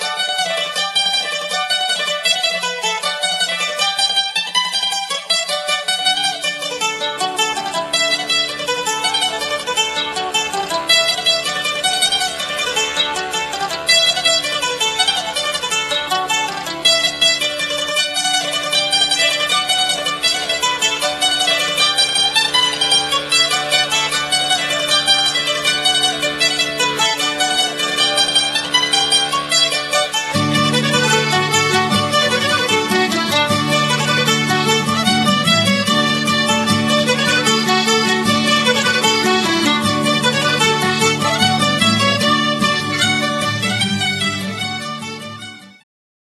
skrzypce
akordeon, flety proste, whistles, cytra
mandolina, gitara akustyczna